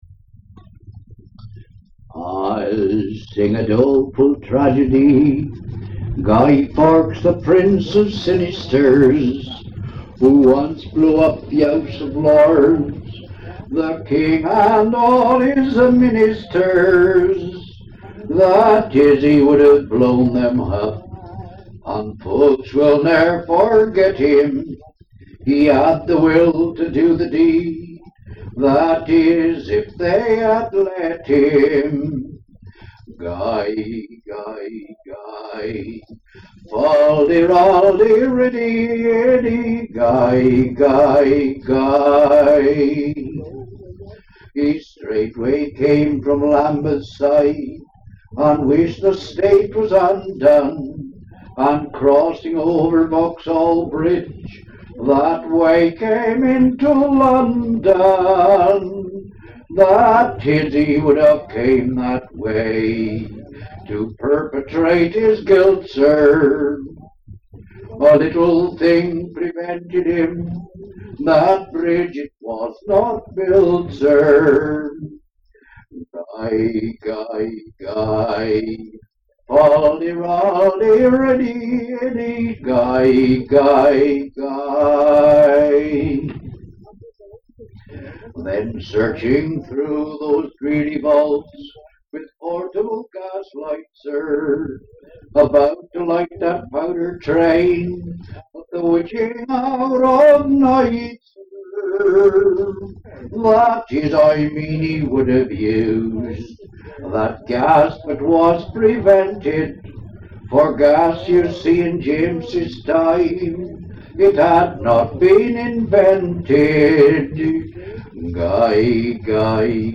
Place Collected: Charlton Kings